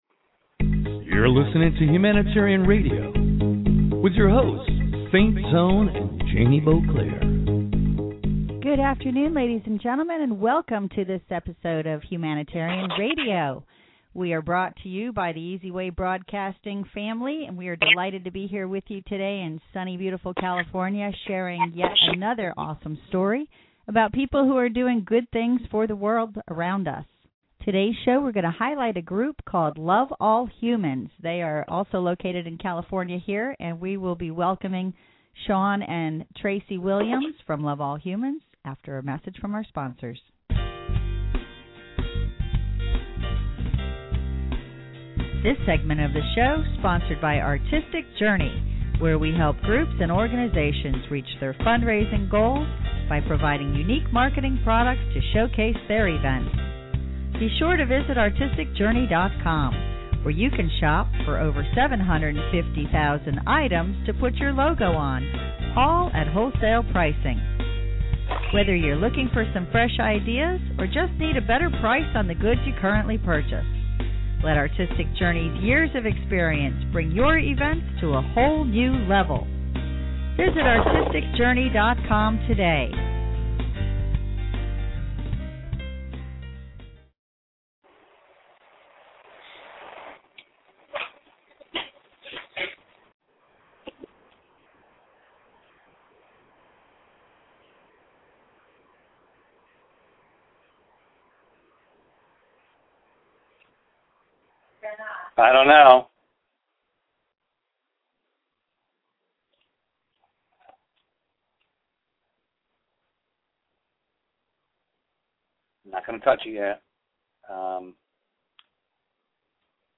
Spirituality HR interview